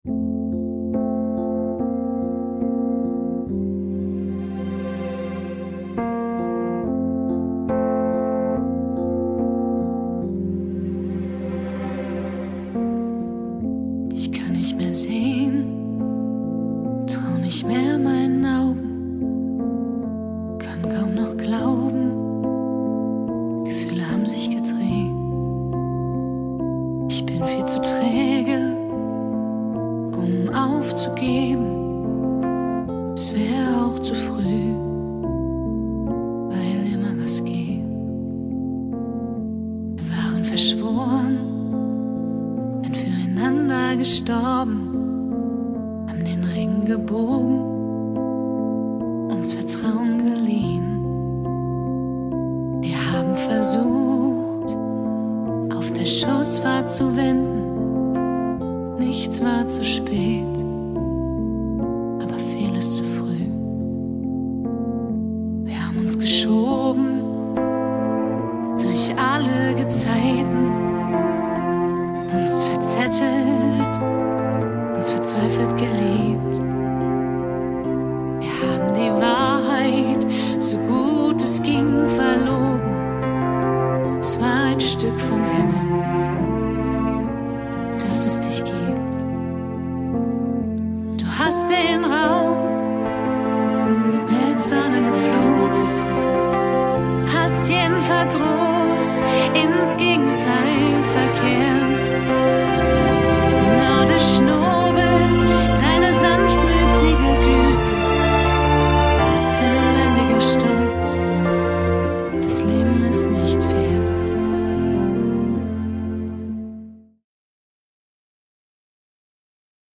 Gefühlvolle Balladen und mehr